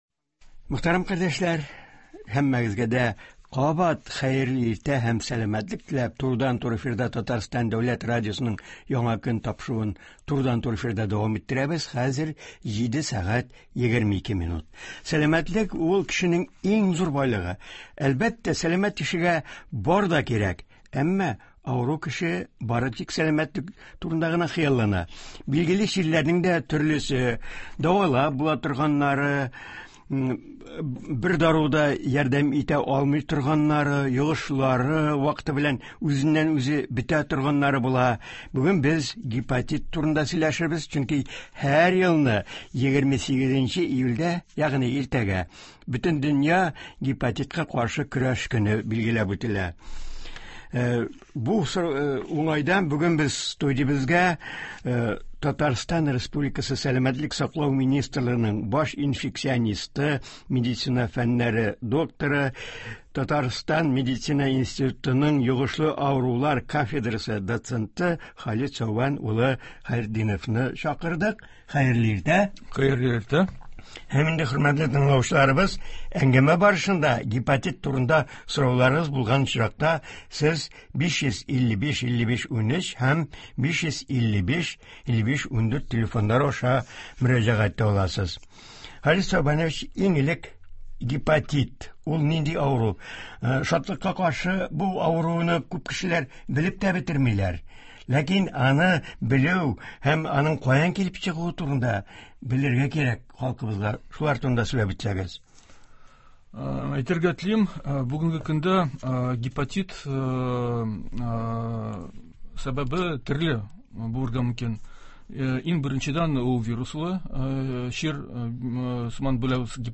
Болар хакында турыдан-туры эфирда медицина фәннәре докторы
тыңлаучылар сорауларына җавап бирәчәк.